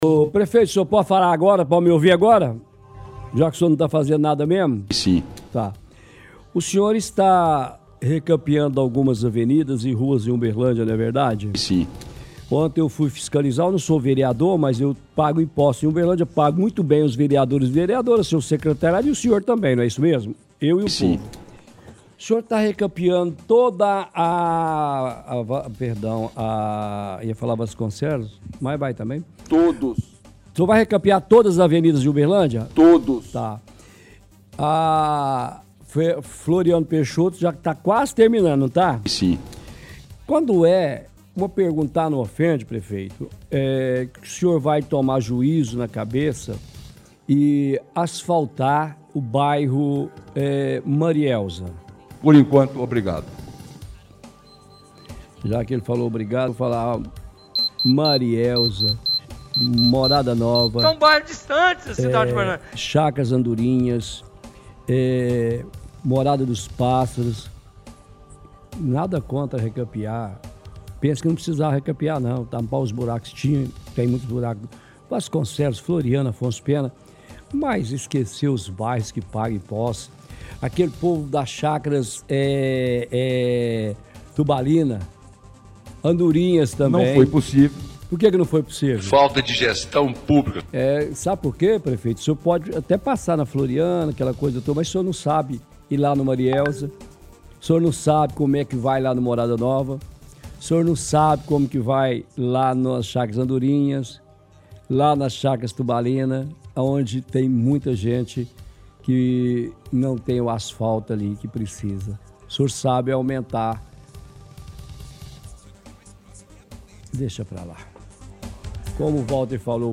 – Transmissão de vários áudios do prefeito utilizados como deboche.